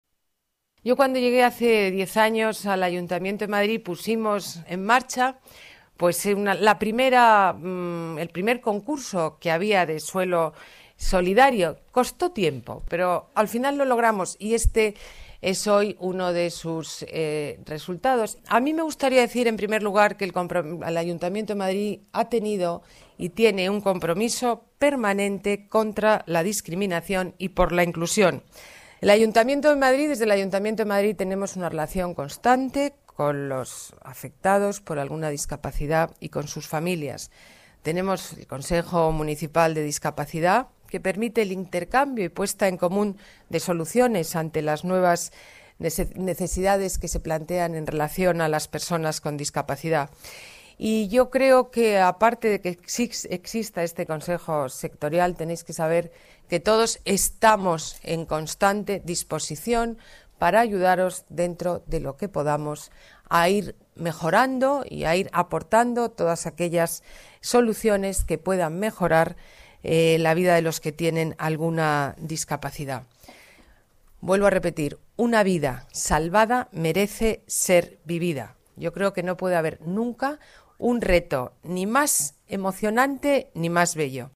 Nueva ventana:Declaraciones alcaldesa de Madrid, Ana Botella: inauguración Centro de Día Carmen Rodríguez